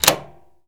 pgs/Assets/Audio/Doors/door_lock_turn_02.wav
door_lock_turn_02.wav